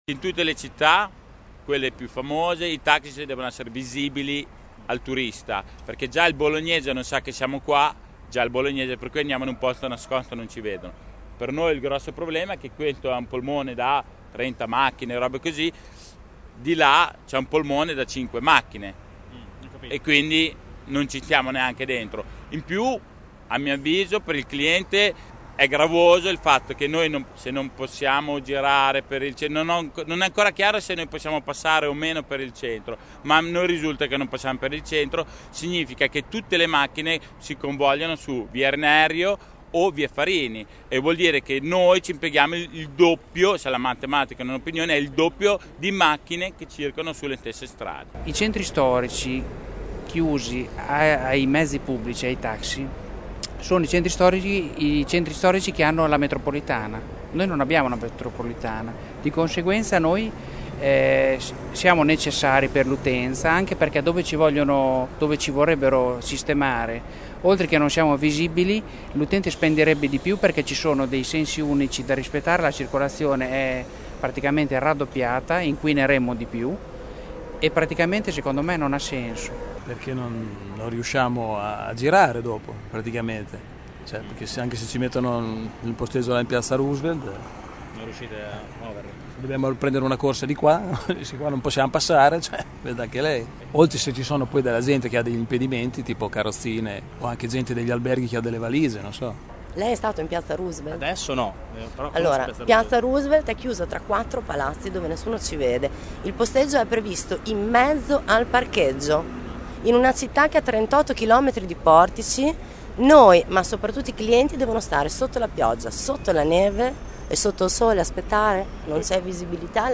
Abbiamo fatto un giro tra i tassisti in sosta in piazza Re Enzo: queste le voci raccolte.
tassisti_sito.mp3